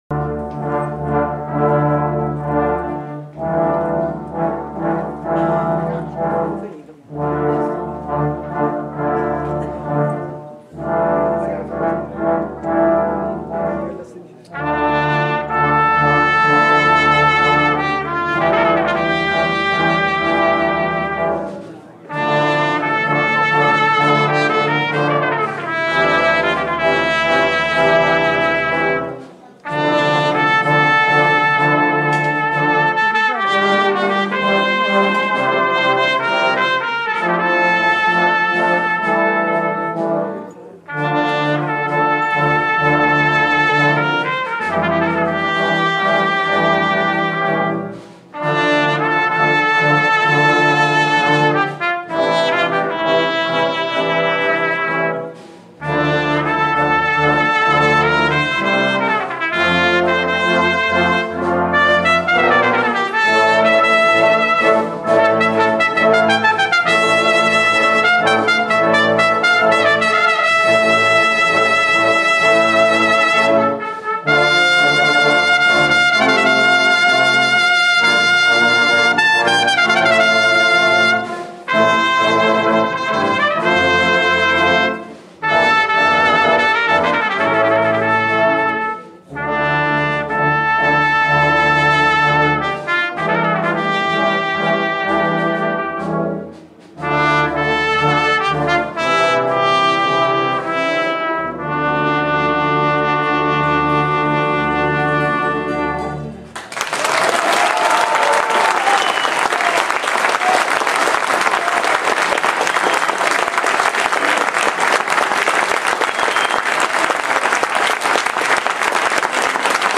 Ascolta il suono dello strumento…